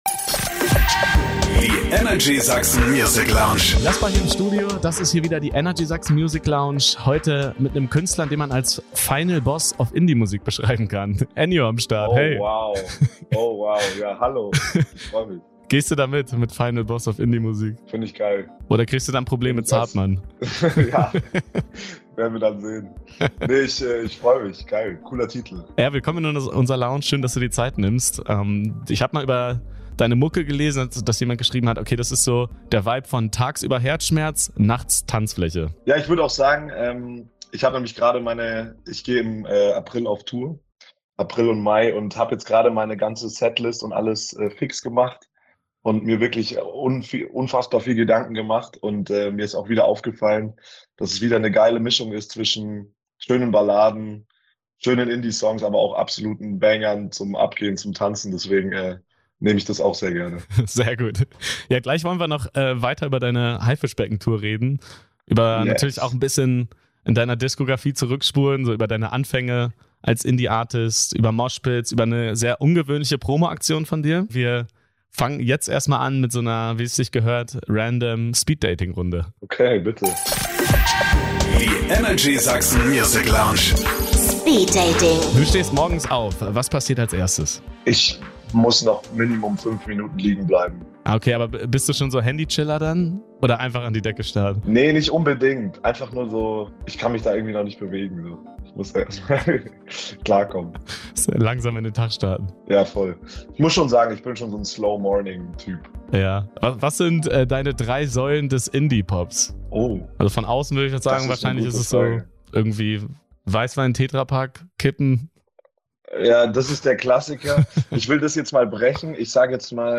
Der Final Boss of Indie ist zu Gast in der Lounge.